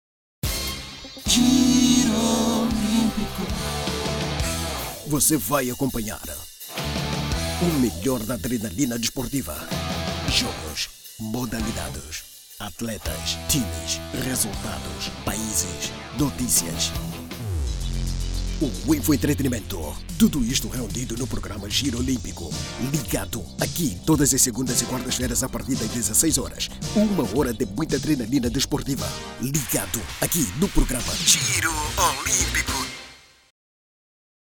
Masculino
Voz Padrão - Grave 00:34